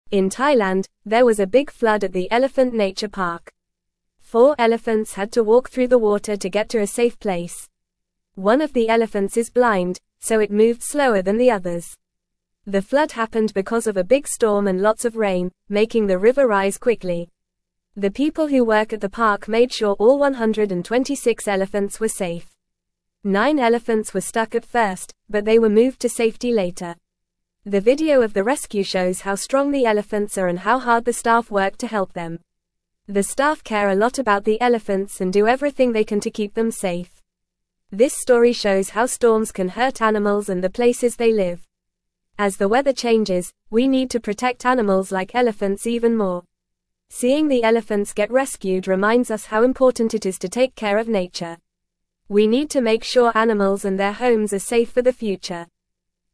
Fast
English-Newsroom-Lower-Intermediate-FAST-Reading-Elephants-Brave-Flood-in-Thailand-People-Keep-Safe.mp3